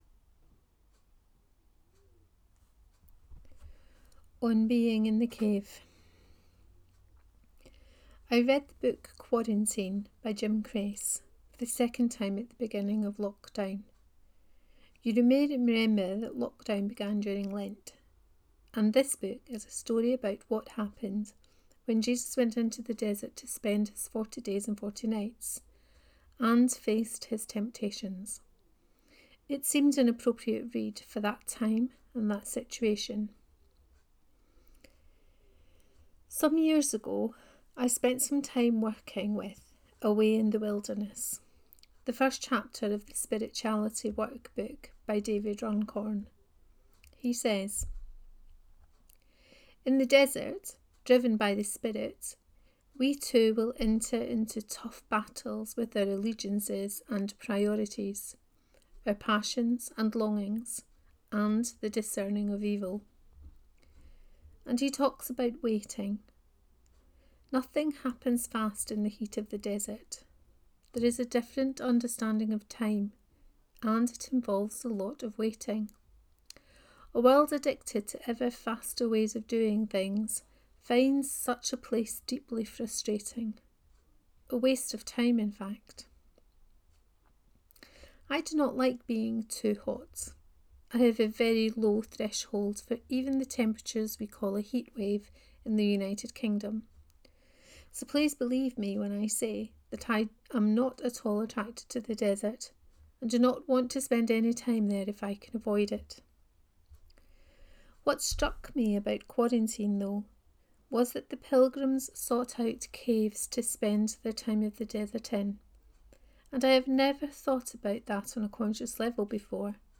On being in the Cave 1: Reading of this post.